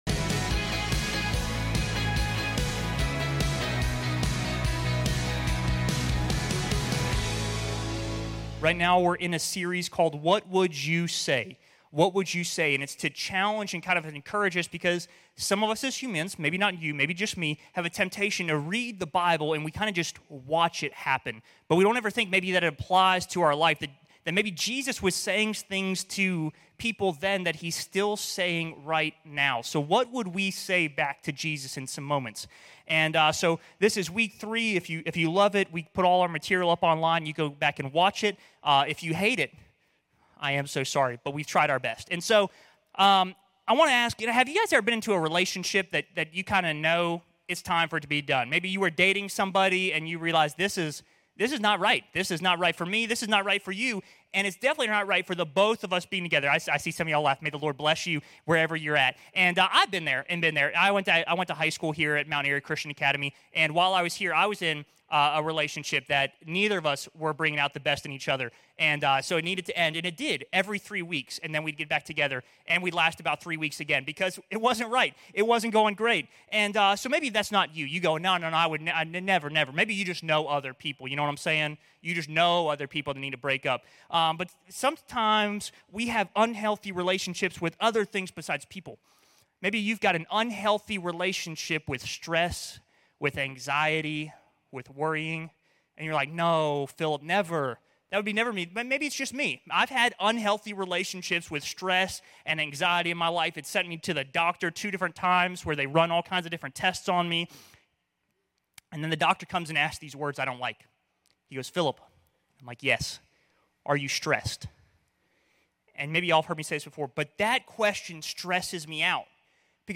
This sermon marks week 3 of "What Would You Say?"